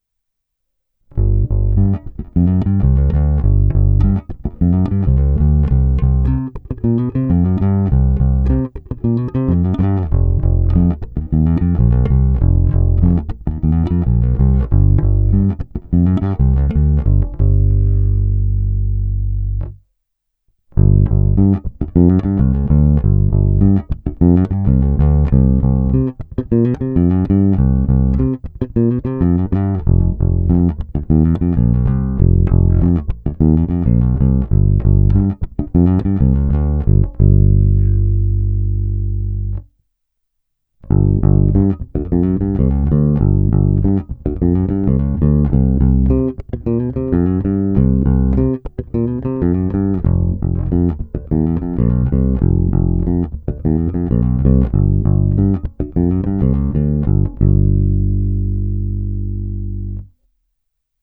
Zvuk je plný, pevný, bohatý středobasový základ doplňuje slušná porce kousavosti.
Není-li uvedeno jinak, následující nahrávky jsou provedeny rovnou do zvukové karty, jen normalizovány, jinak ponechány bez úprav. Tónová clona vždy plně otevřená.